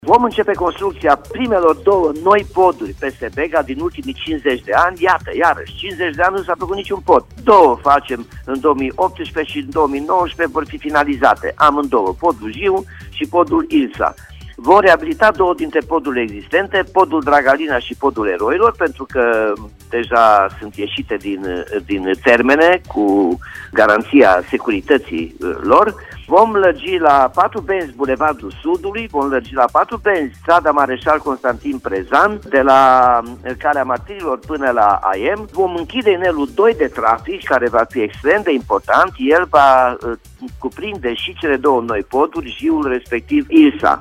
Anunţul a fost făcut de primarul Nicolae Robu, la Radio Timişoara, care a precizat că Timişoara va avea două poduri noi după mai bine de 50 de ani.